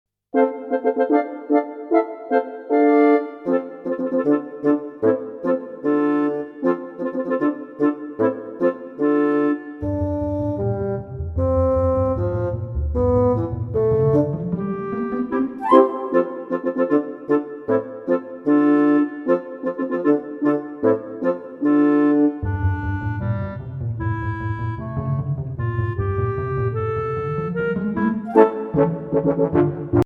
Voicing: Flute w/ Audio